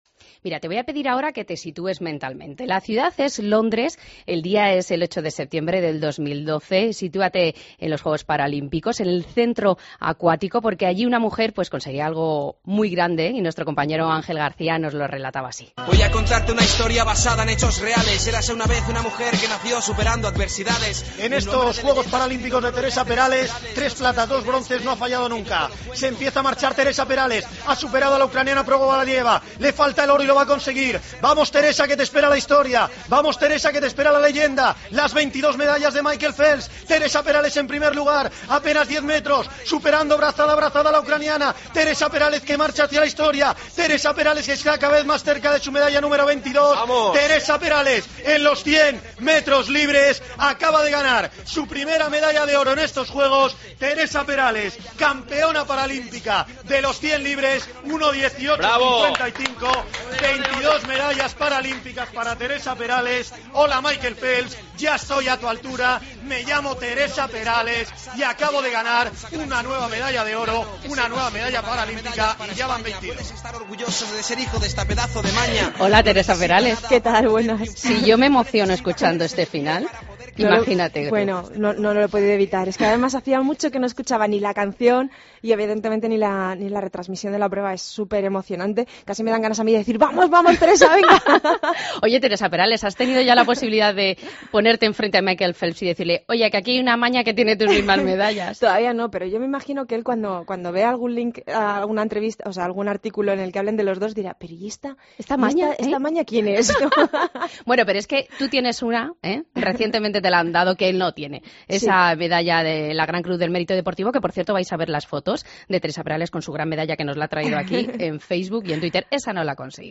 Entrevista a Teresa Perales, atleta paralímpica en Fin de Semana COPE